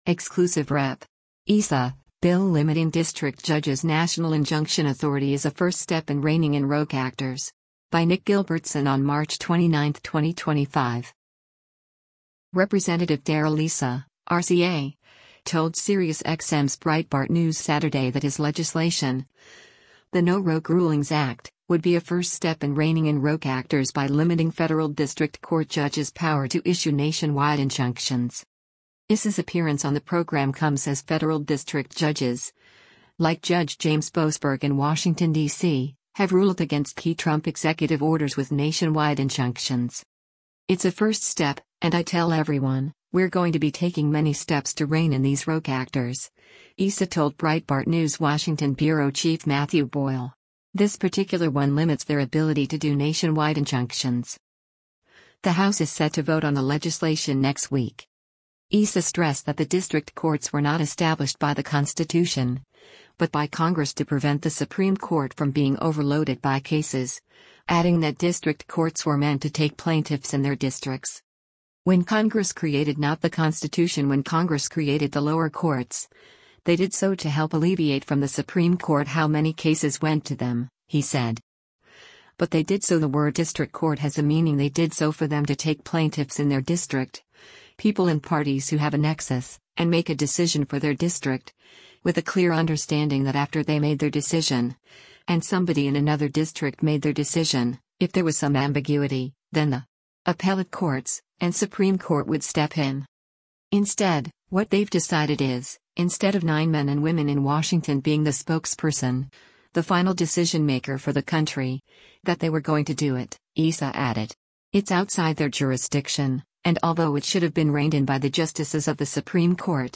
Rep. Darrell Issa (R-CA) told Sirius XM’s Breitbart News Saturday that his legislation, the “No Rogue Rulings Act,” would be a “first step” in reining in “rogue actors” by limiting federal district court judges’ power to issue nationwide injunctions.
Breitbart News Saturday airs on SiriusXM Patriot 125 from 10:00 a.m. to 1:00 p.m. Eastern.